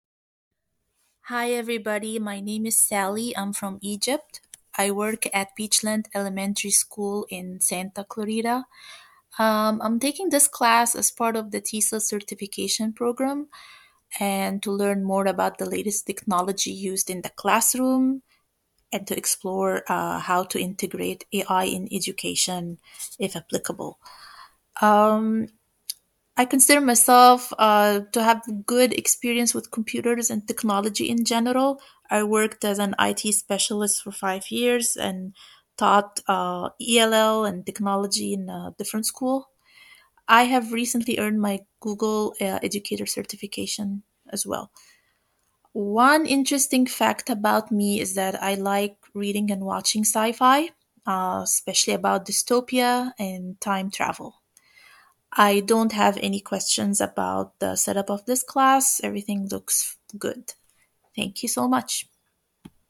Vocaroo is a free online voice recording.